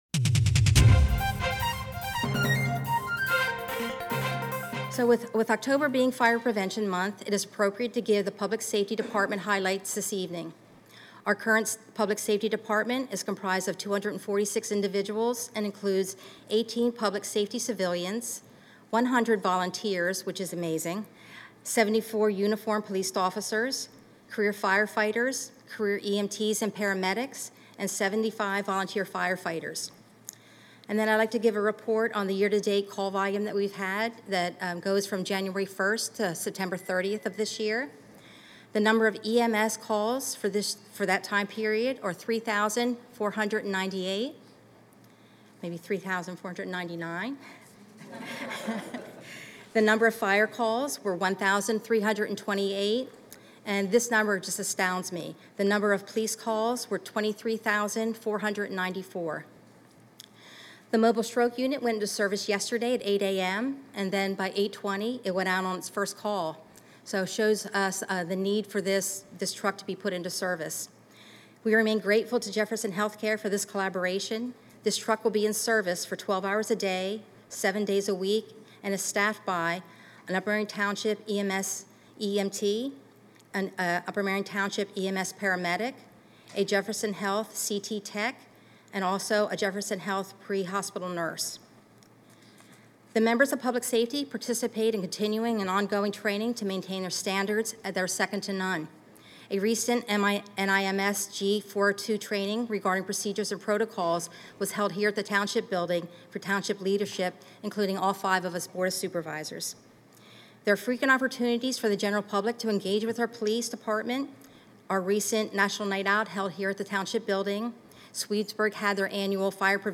Public Safety Swearing-In Ceremonies